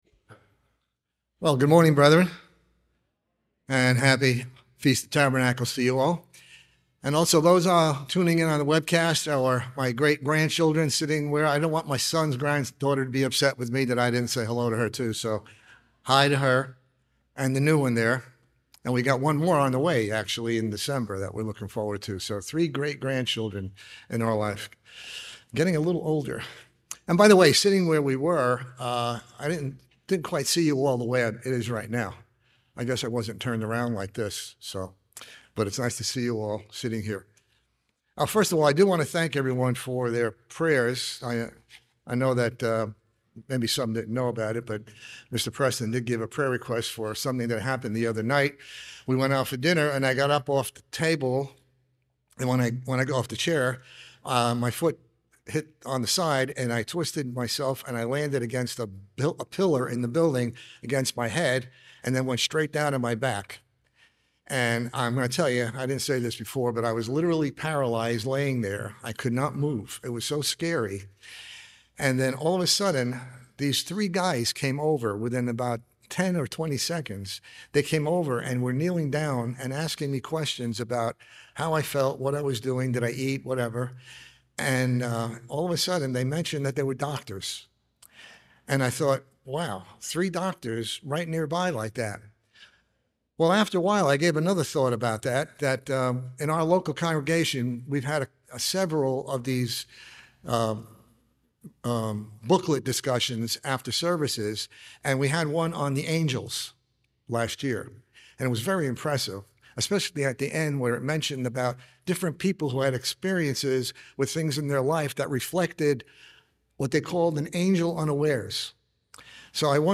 This sermon was given at the Panama City Beach, Florida 2023 Feast site.